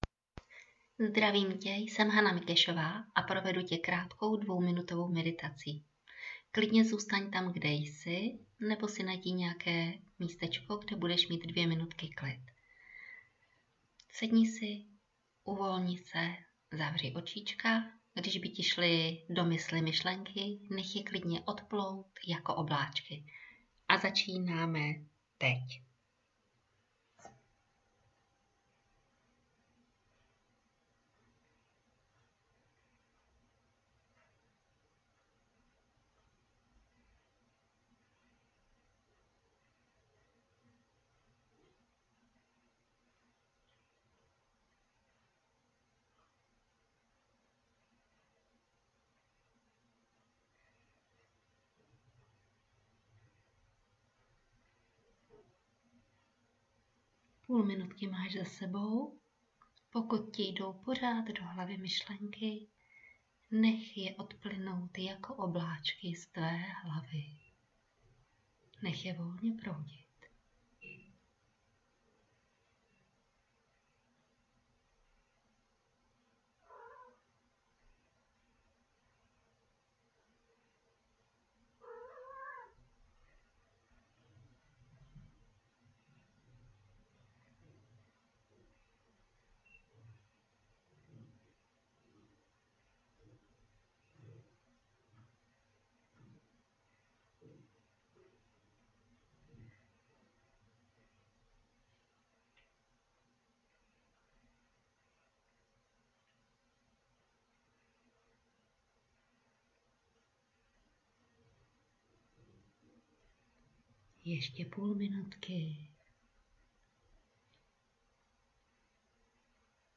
meditace
Ideální je párkrát za den Meditace 2 minutová.
Meditace-2minutová.wav